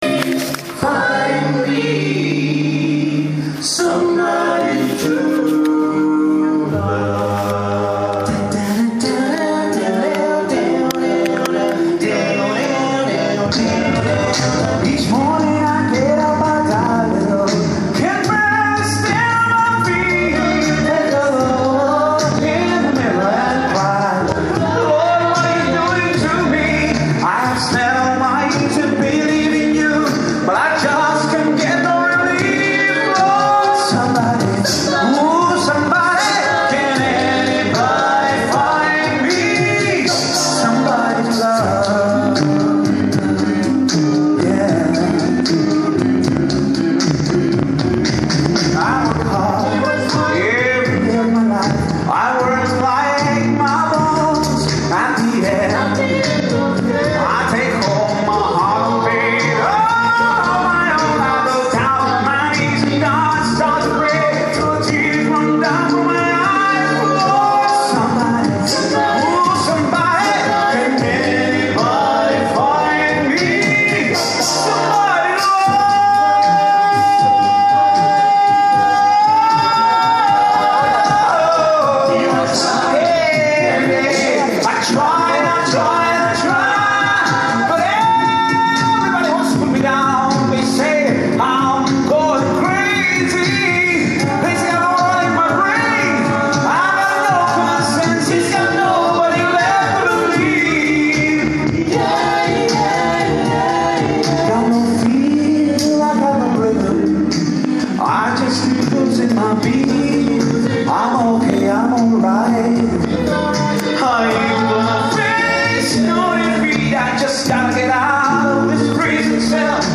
En el marco de la entrega de Becas al Mérito, hizo su presentación estelar Voxpop una banda con siete cantantes a capella que brindan “una música refinada y llena de energía con un humor desopilante.”
El broche de oro esperado se llevó todos los aplauso de la sala y fue el toque especial que tuvo la noche del cierre.
Presentación y tema musical.